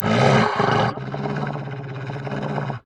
bdog_growl_0.ogg